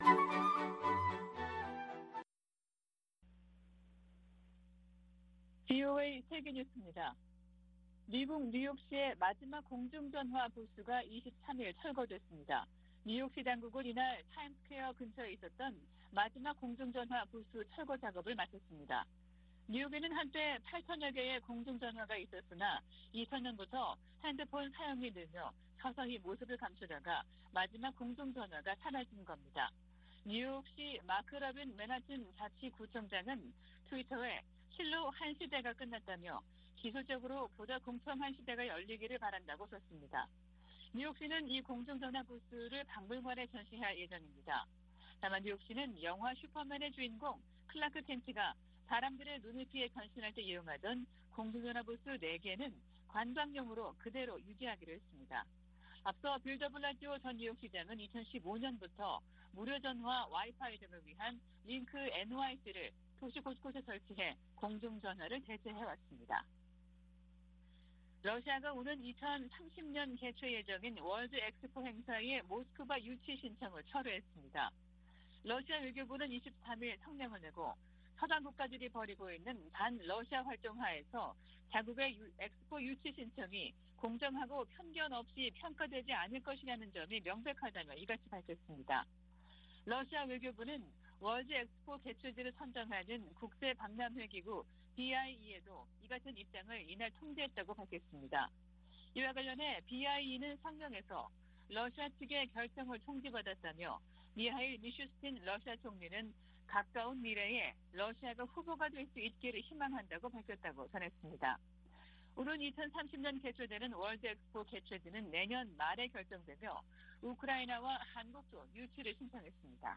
VOA 한국어 아침 뉴스 프로그램 '워싱턴 뉴스 광장' 2022년 5월 25일 방송입니다. 도쿄에서 열린 '쿼드' 정상회의는 한반도의 완전한 비핵화 달성의지를 재확인했습니다. 미국 여야 의원들은 조 바이든 대통령이 방한 중 대규모 투자유치 성과를 냈다며, 대북 최대 압박을 복원해야 한다는 조언을 제시했습니다. 바이든 대통령은 한일 순방으로 다양한 경제, 안보 협력을 추진하면서 중국의 압박을 차단하는 효과를 거두고 있다고 미국 전문가들이 평가했습니다.